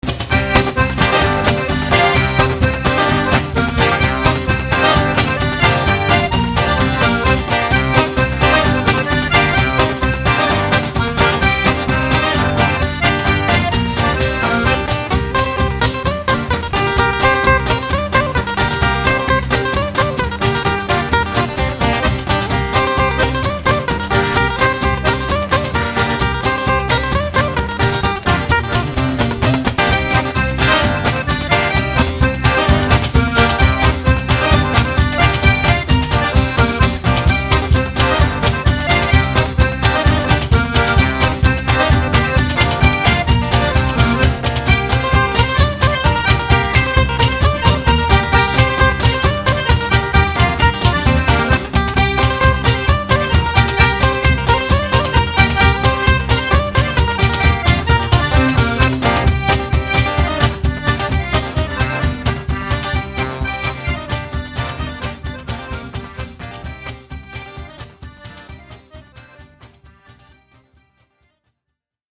(tradit.) Rec. live 1999 (instr.)